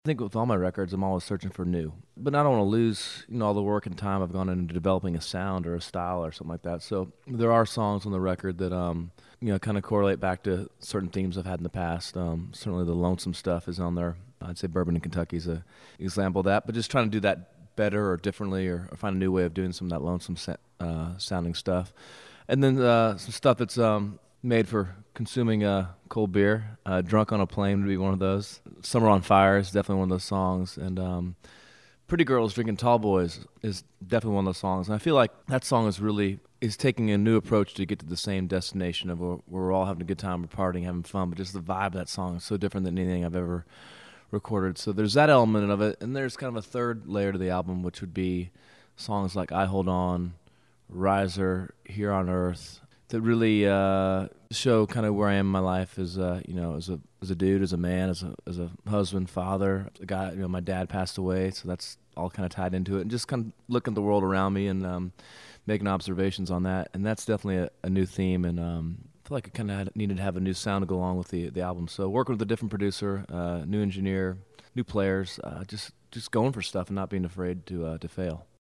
AUDIO: Dierks Bentley talks about his forthcoming album, Riser, due in stores February 25th.